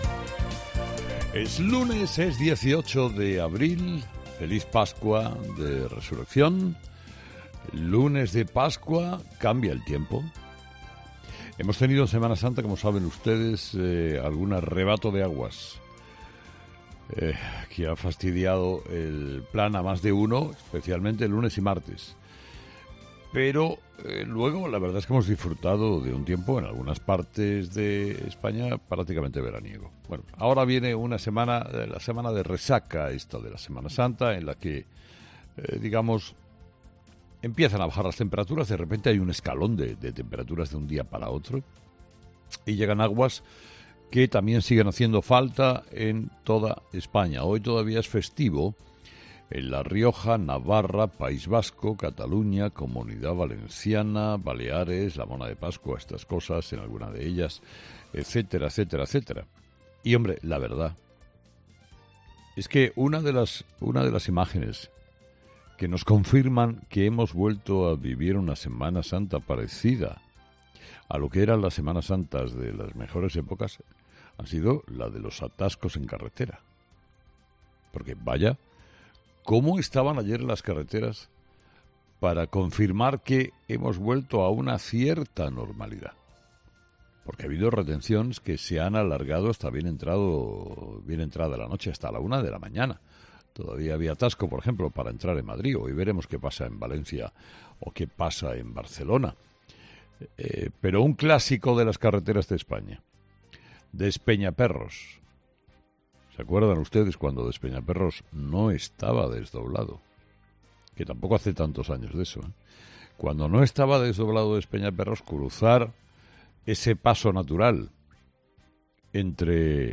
El presentador de 'Herrera en COPE' analiza las claves tras la vuelta de la Semana Santa